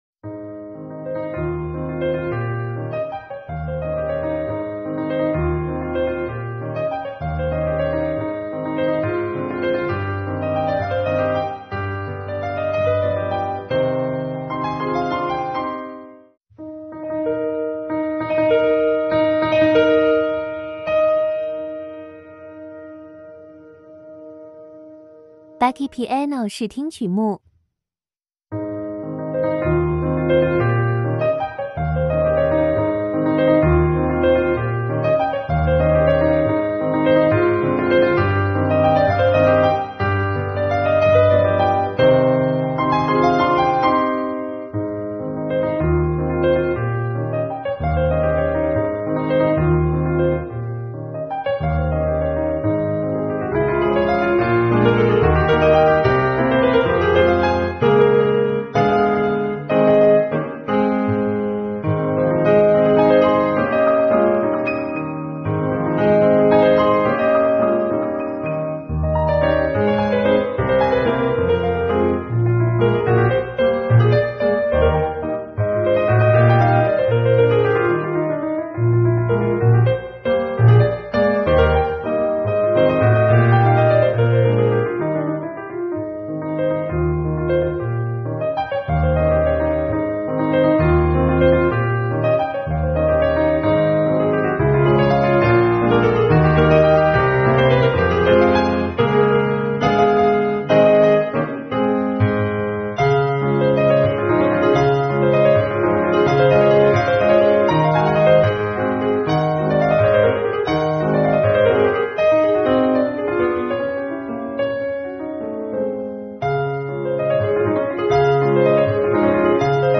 优雅浪漫